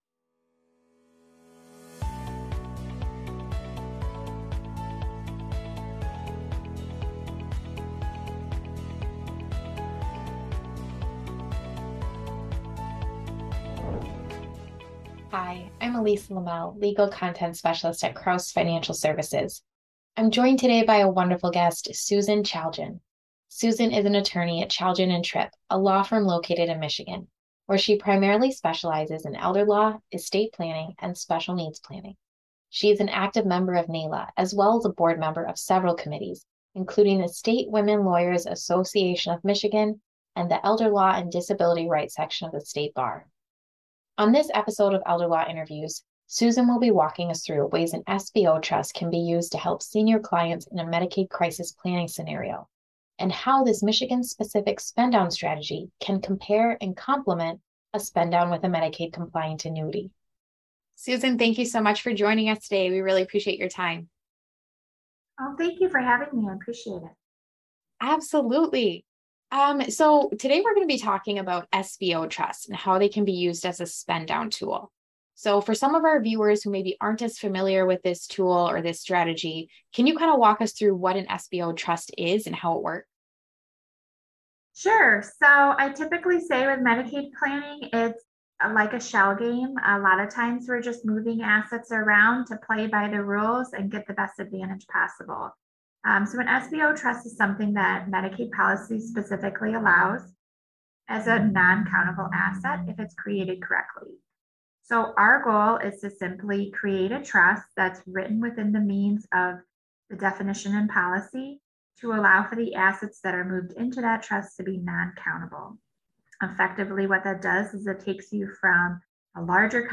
In this Elder Law Interview